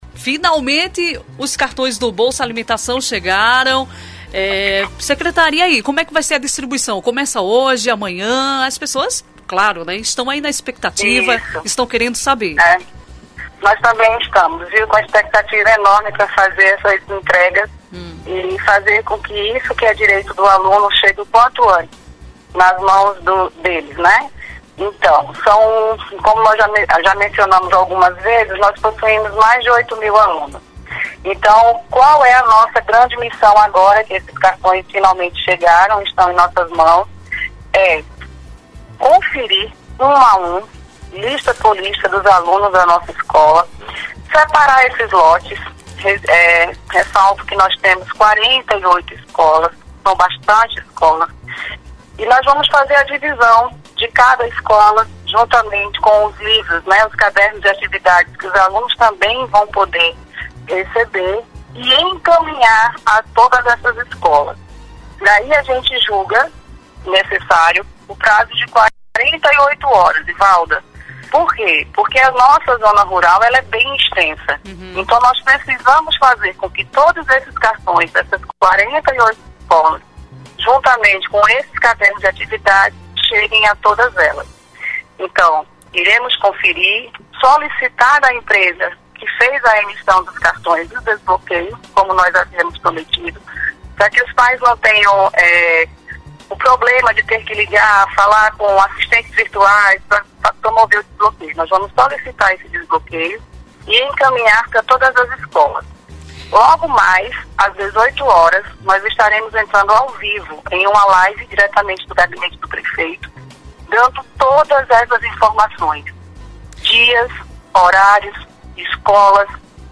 entrevista-secretaria-cartao-0207.mp3